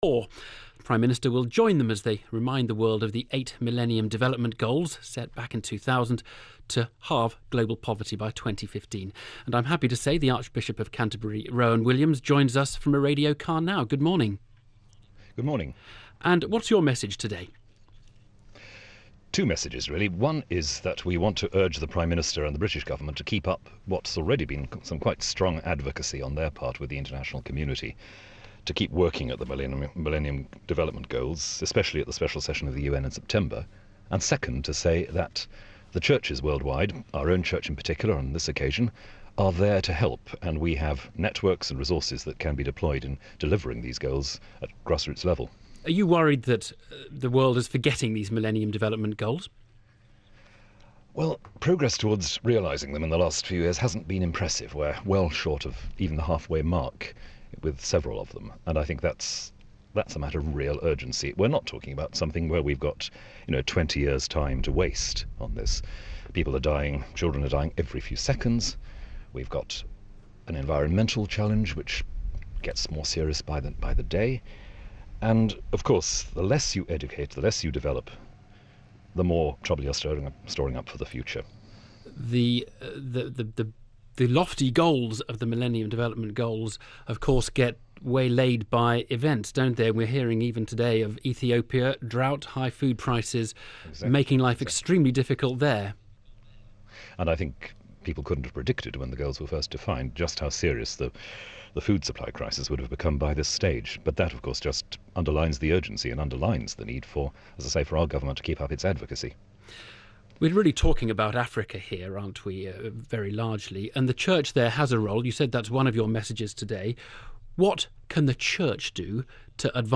'Millennium Development Goals' BBC Radio 4 Today Programme interview